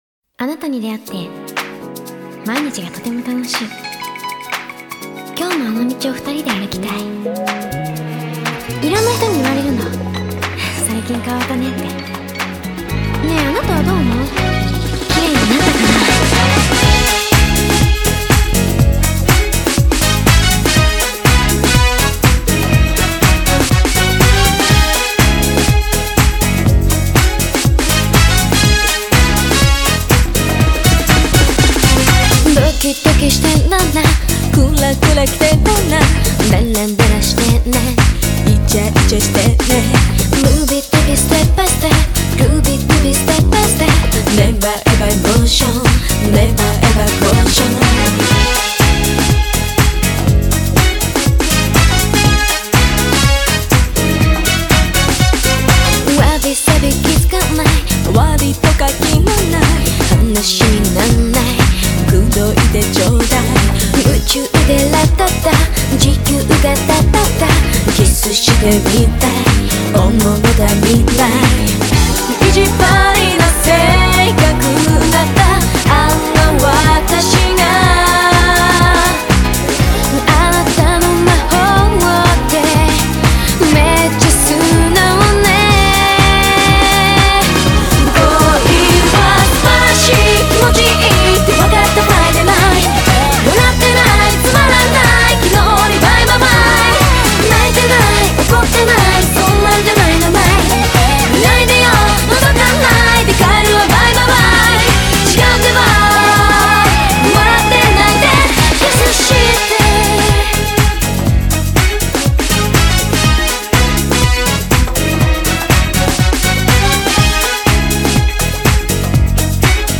BPM122
Audio QualityPerfect (High Quality)
fun disco-style song